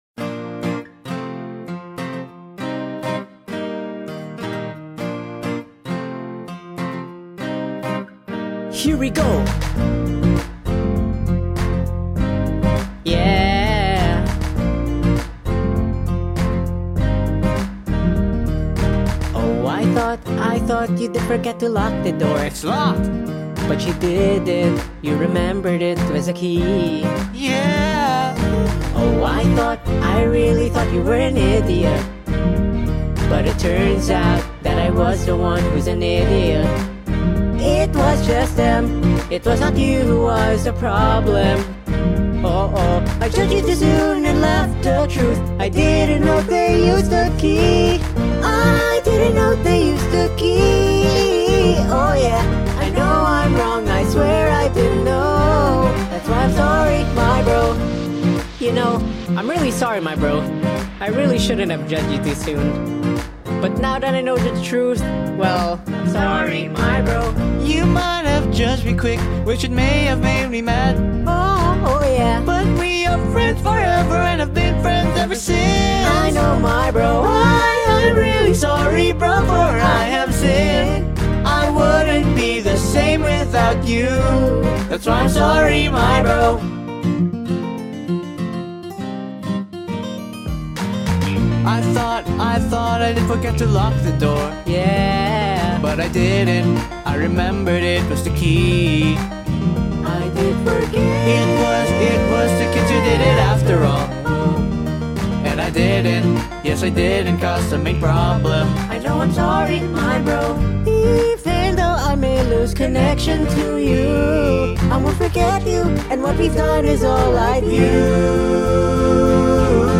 BPM100
Audio QualityPerfect (High Quality)
Comments(FULL SONG)